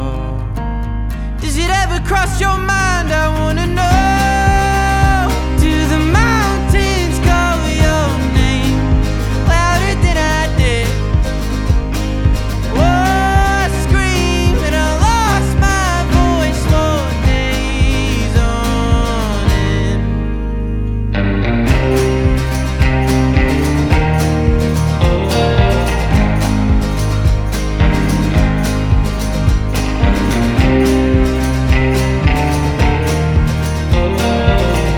Жанр: Альтернатива / Фолк-рок